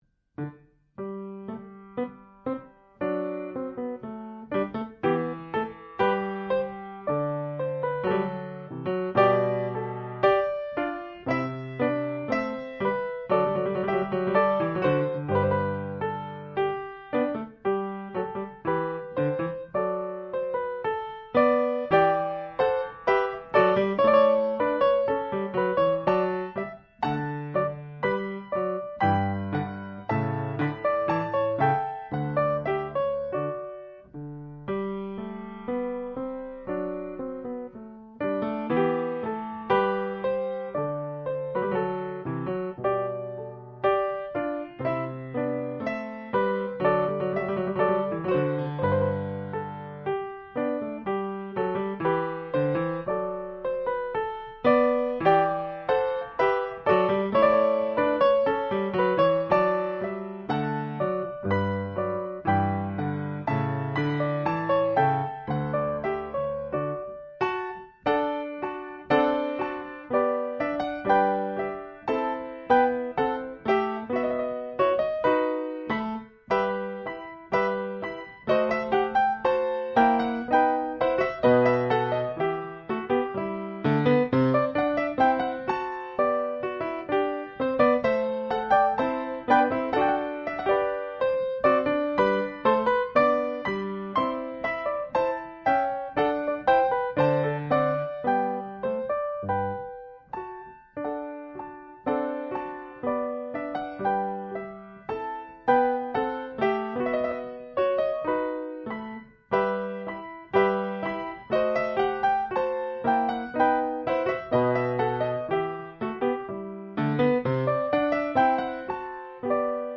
Pianoforte
Pianoforte Steinway a coda - modello S.155.